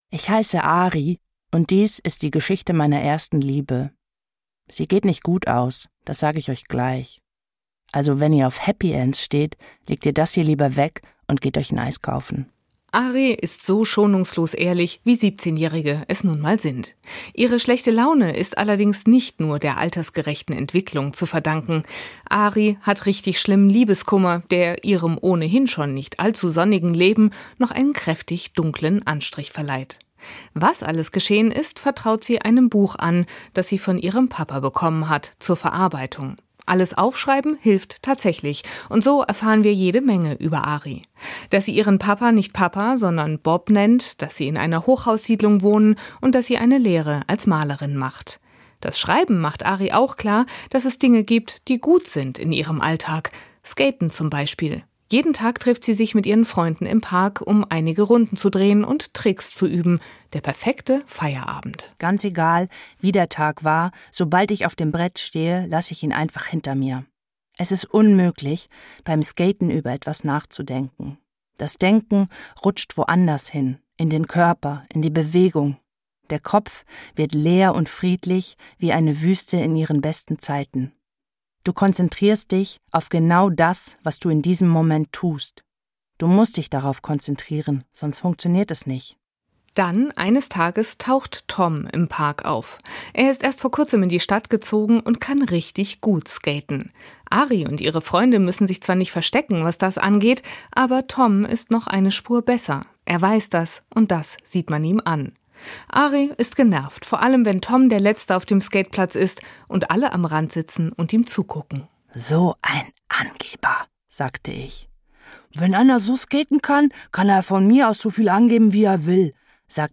Hörbuch
mit jugendlicher Hingabe: Schnodderig, abgeklärt und gleichzeitig verwundert über alles, was das Leben so zu bieten hat.